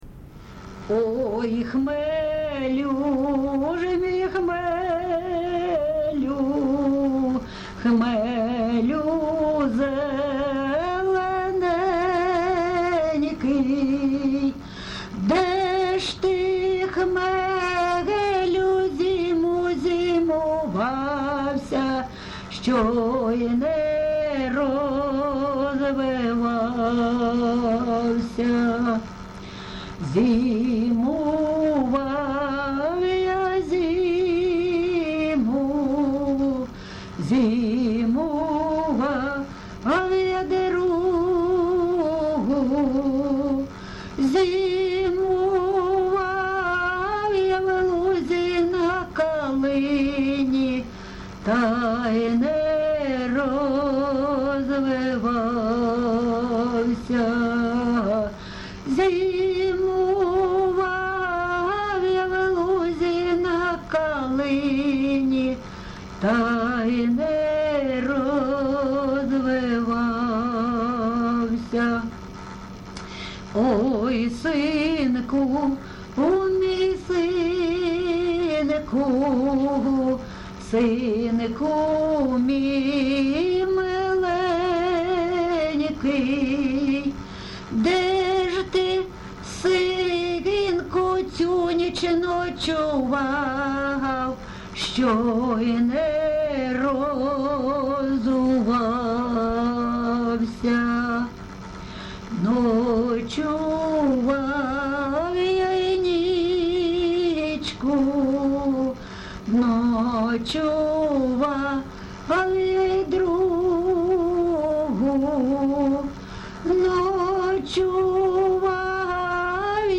ЖанрПісні з особистого та родинного життя
Місце записус. Лозовівка, Старобільський район, Луганська обл., Україна, Слобожанщина